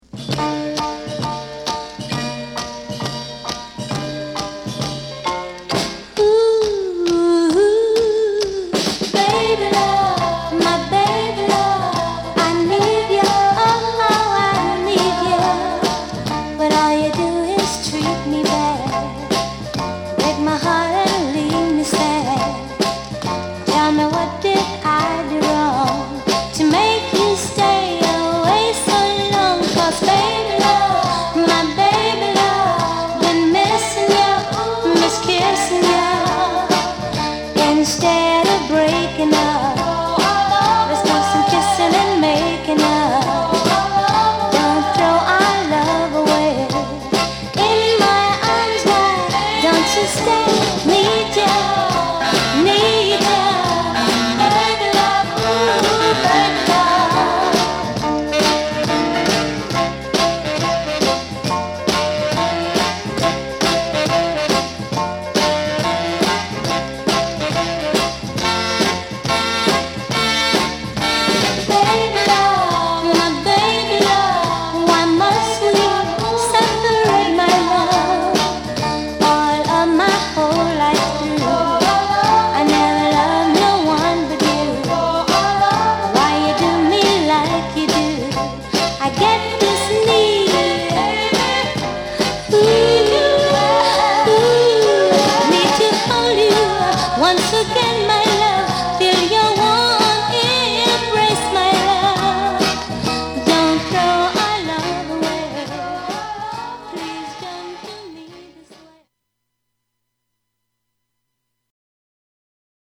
それだけでモータウンの空気が部屋に満ちる。